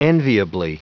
Prononciation du mot enviably en anglais (fichier audio)
Prononciation du mot : enviably